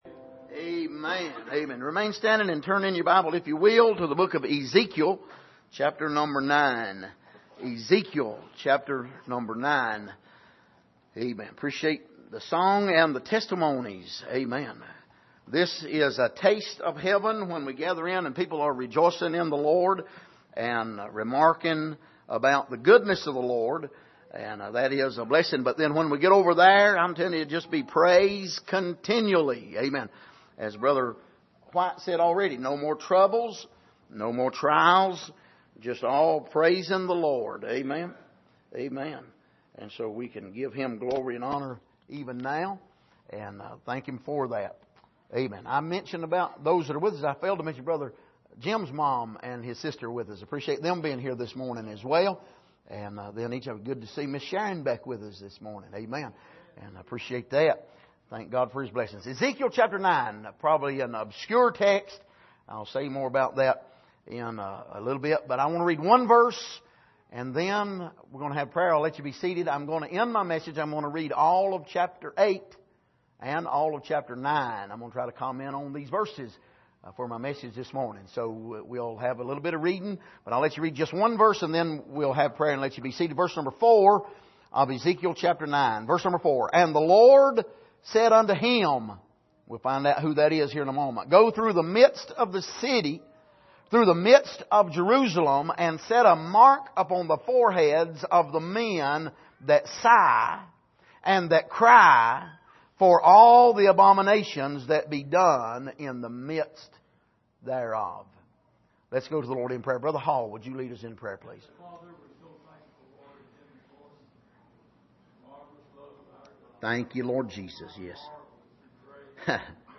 Passage: Ezekiel 9:4 Service: Sunday Morning